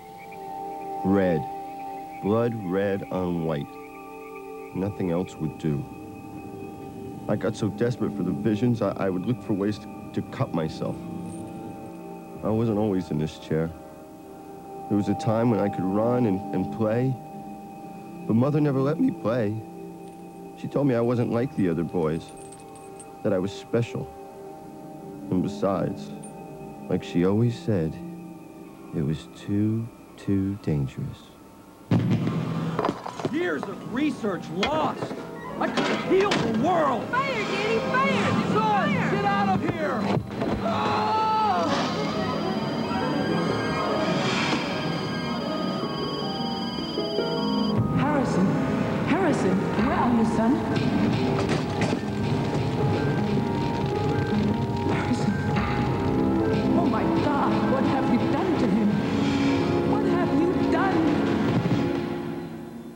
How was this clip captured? Hello again. I managed to copy my VHS tape to DVD and extract the audio. english.vhs.sample.wav